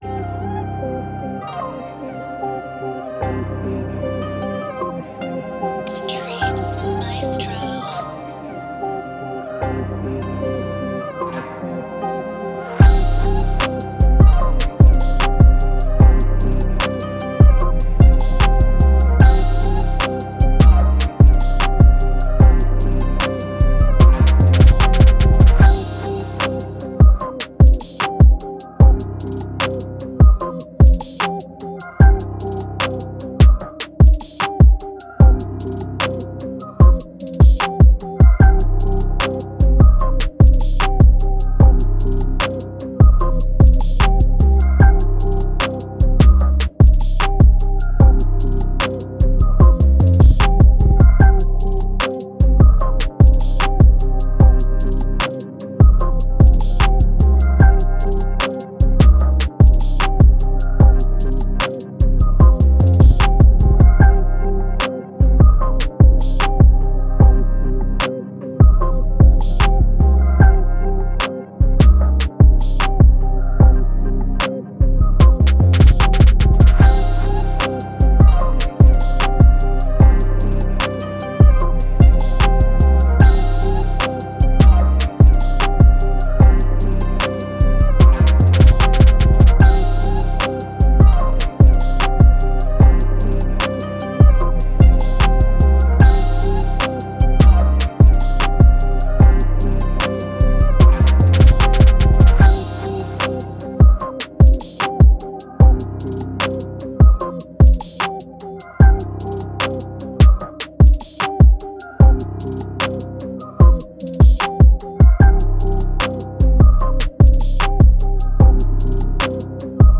Dramatic Type Beat
Moods: dramatic, hard, dark
Genre: Rap
Tempo: 140